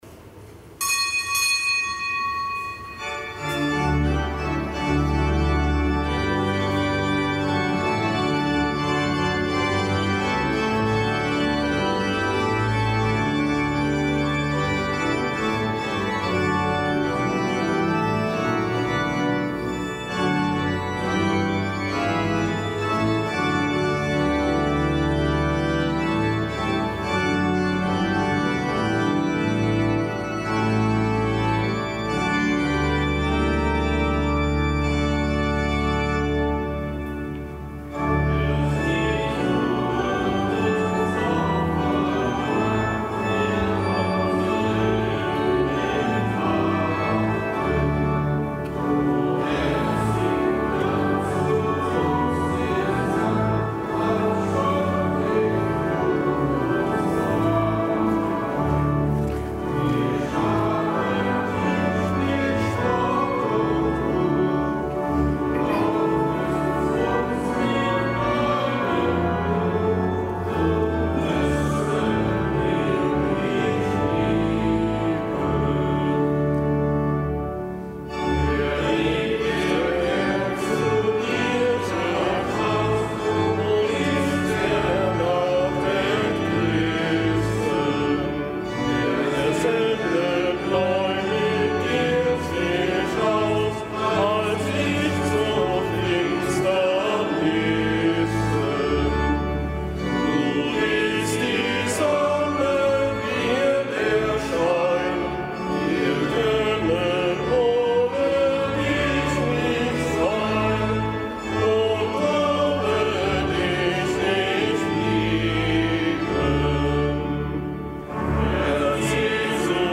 Kapitelsmesse aus dem Kölner Dom am Freitag der siebten Osterwoche (Herz-Jesu-Freitag). Nichtgebotener Gedenktag des Heiligen Norbert von Xanten, Ordensgründer, Bischof von Magdeburg (RK; GK).